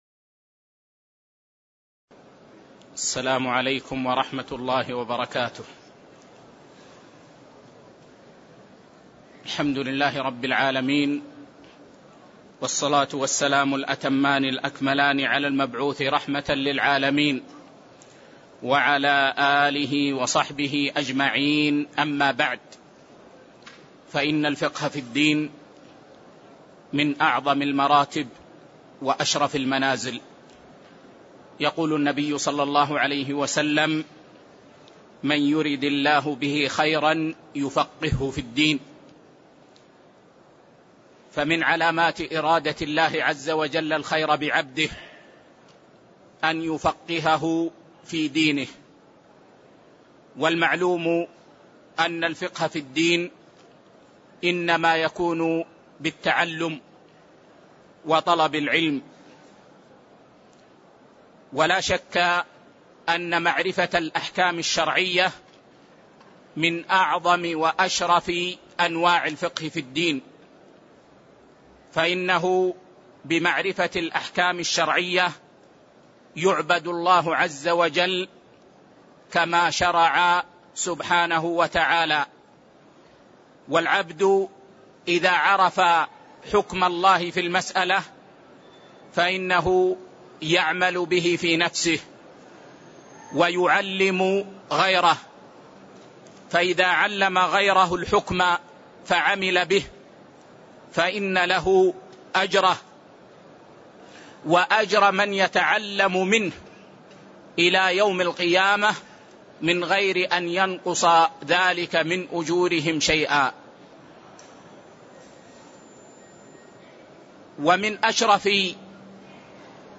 تاريخ النشر ١٩ ذو القعدة ١٤٣٤ هـ المكان: المسجد النبوي الشيخ